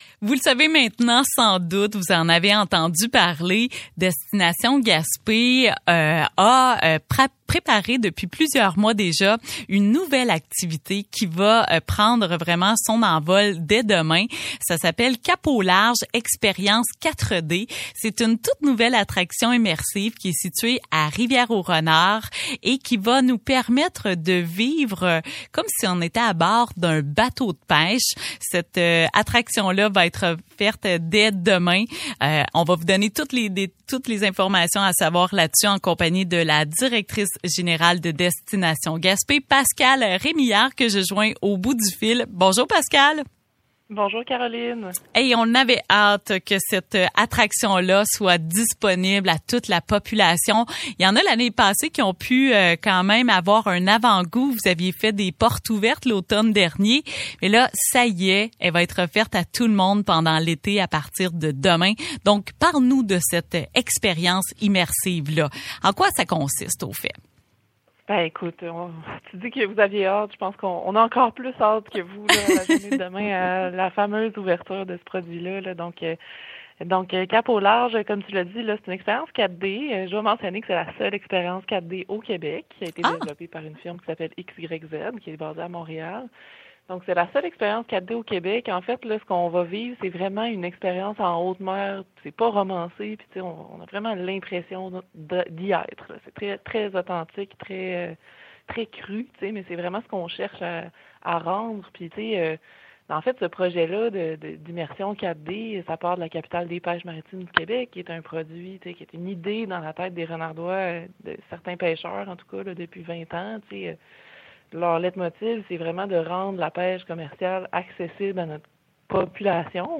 Radio-Gaspésie